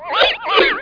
00424_Sound_swirl3